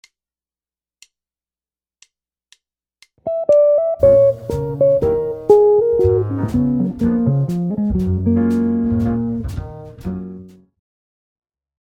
the Db7 (sounds like Db13).